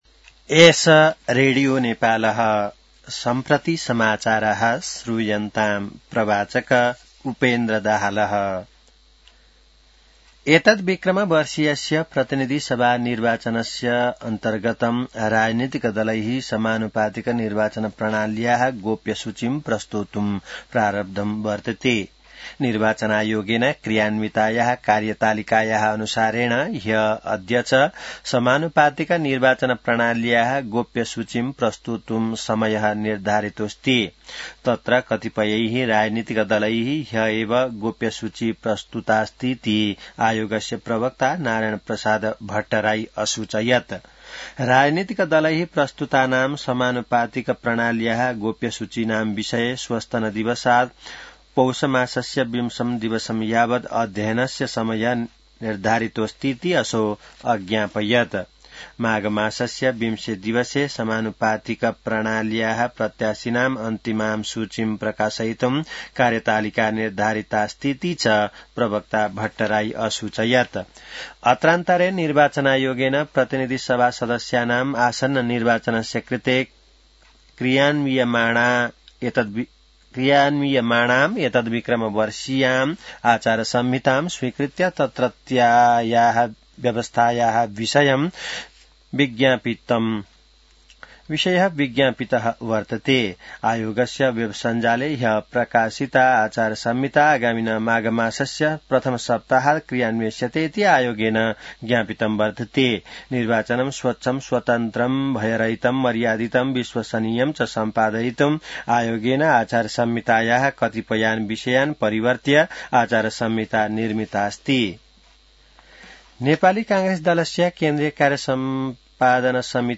An online outlet of Nepal's national radio broadcaster
संस्कृत समाचार : १४ पुष , २०८२